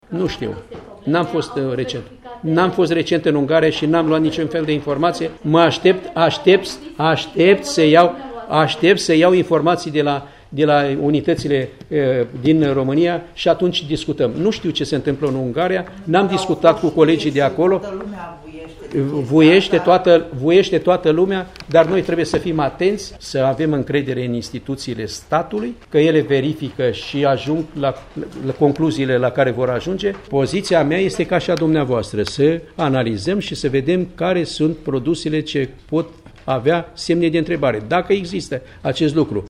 Ministrul Agriculturii, aflat în vizită în Târgu-Mureș, a anunțat într-o conferință de presă că, începând de astăzi, o echipă mixtă formată din reprezentanți ai Ministerului Agriculturii, Autorității Naționale pentru Protecția Consumatorului (ANPC) și ai Autorității Naționale Sanitar-Veterinare și pentru Siguranța Alimentelor (ANSVSA) vor stabili cum vor fi realizate controale în perioada următoare.
La insistențele jurnaliștilor,ministrul Petre Daea a spus că nu cunoaște situația din Ungaria, țară care a reclamat vehement faptul că produse de slabă calitate sunt aduse în magazine: